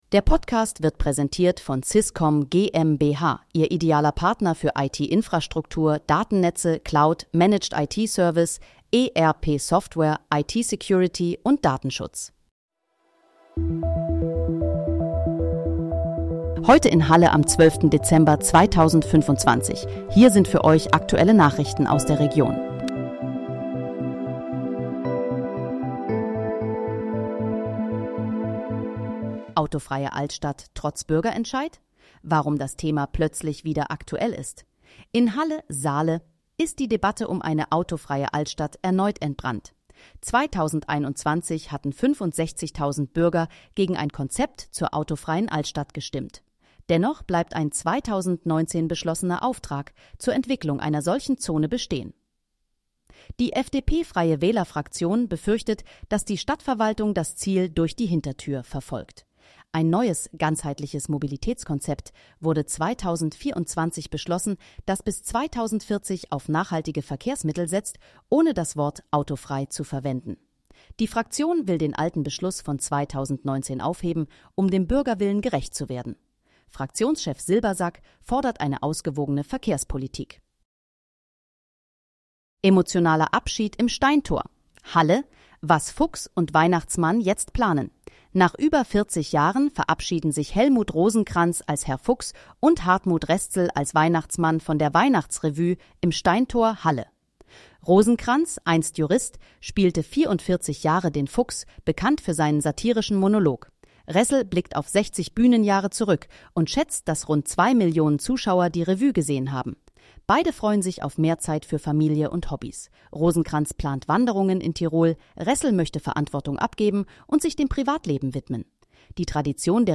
Heute in, Halle: Aktuelle Nachrichten vom 12.12.2025, erstellt mit KI-Unterstützung
Nachrichten